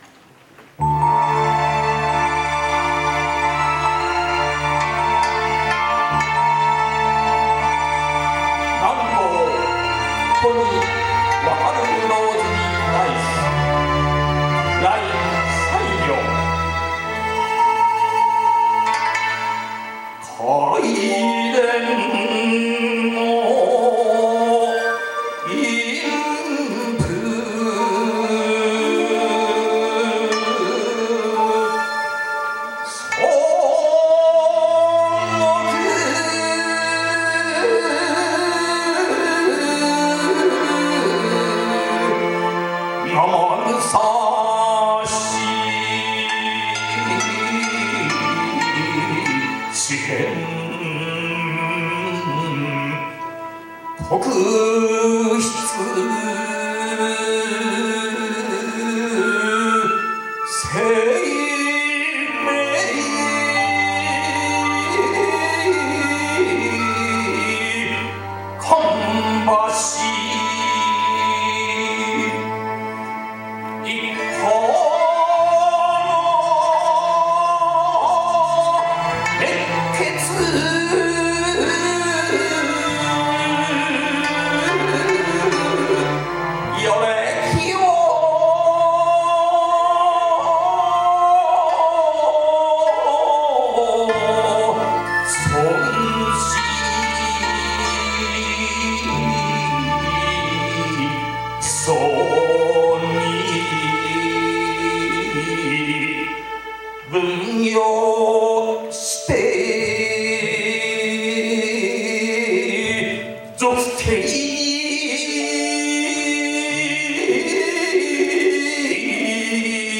そして正午より第５０回吟士権コンクール決勝大会が行われました。